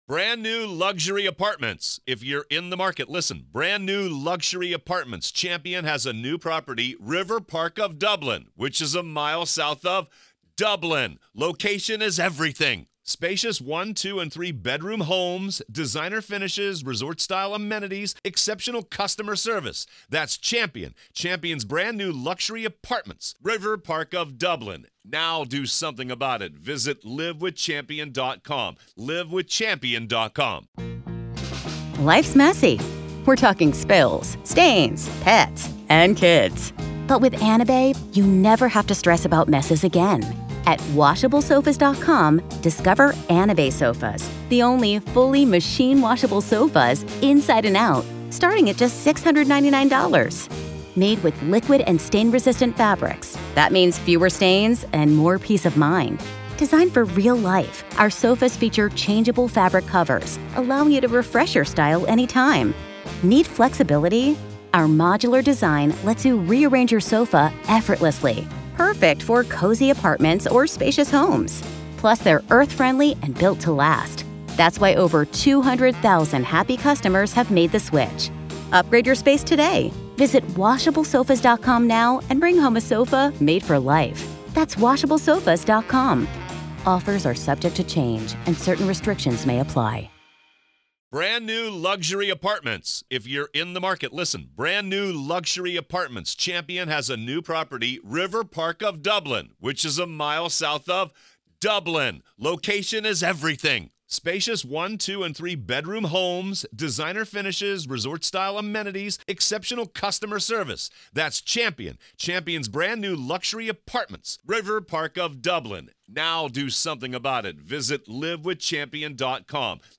Alex Murdaugh Trial: Courtroom Coverage | Day 4, Part 1